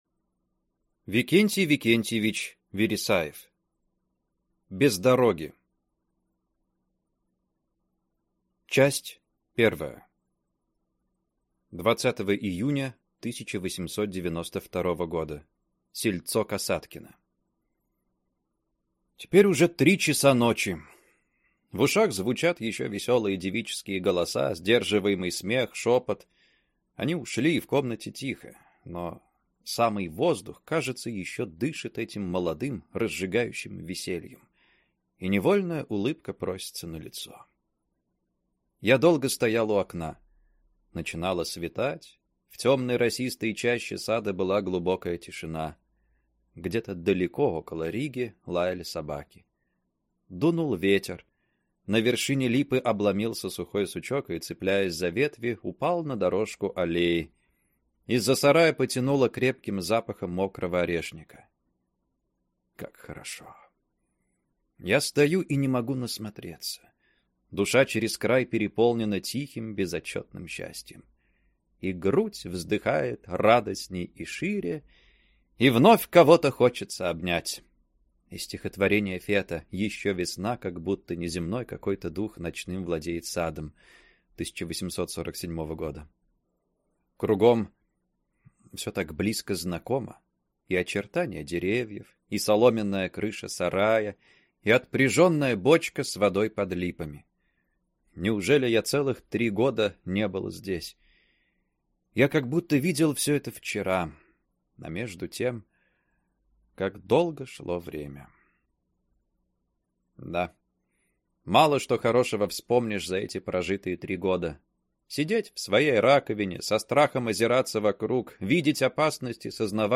Аудиокнига Без дороги | Библиотека аудиокниг